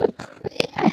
Minecraft Version Minecraft Version 1.21.5 Latest Release | Latest Snapshot 1.21.5 / assets / minecraft / sounds / mob / endermen / idle4.ogg Compare With Compare With Latest Release | Latest Snapshot